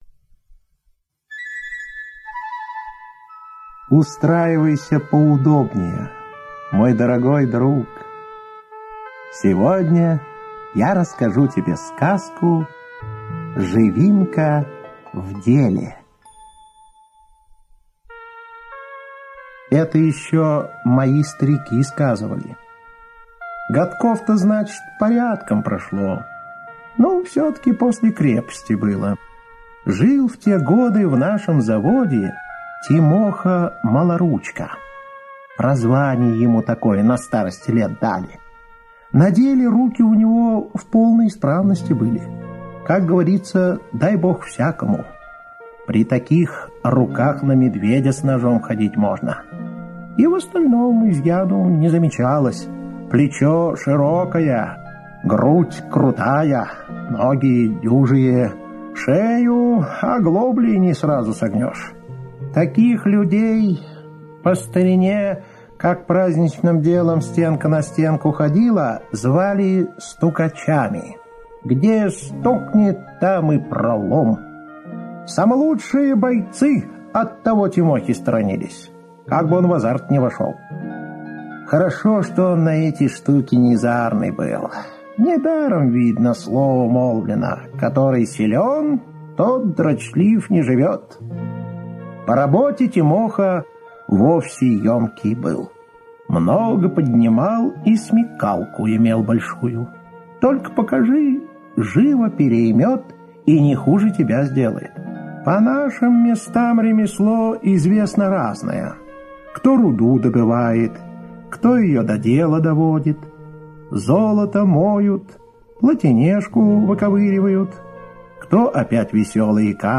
Слушайте Живинка в деле - аудиосказка Бажова П. Сказ про рабочего парня Тимоху, который решил всякое мастерство своей рукой изведать.